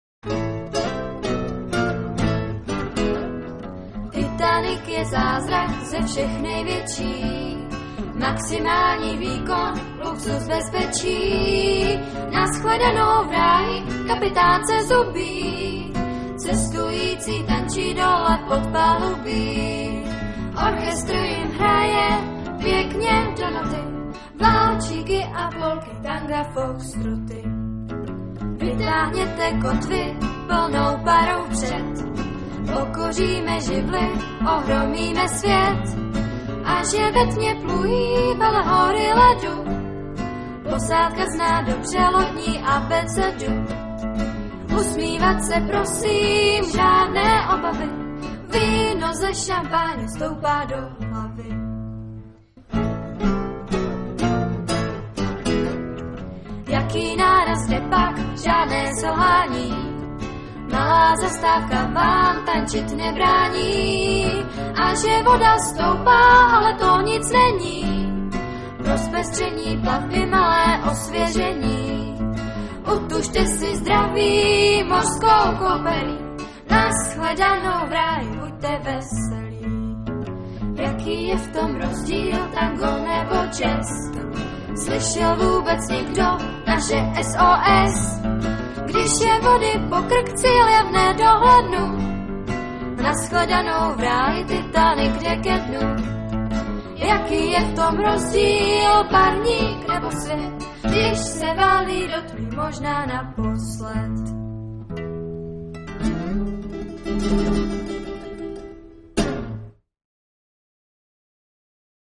TÁBOR/SEZIMOVO ÚSTÍ - V loňském roce na táborské Bambiriádě 2006 opět vystoupili vítězové Dětské porty z Českého Krumlova - kapela LÉTAVICE z Českých Budějovic